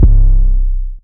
Southside 808 (6).wav